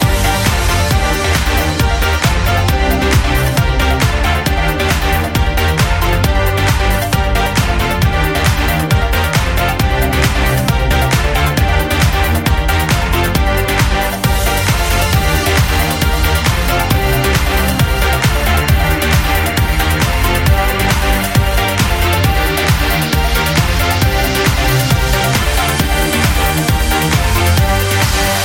Genere: dance, house, electro, club, remix, 2008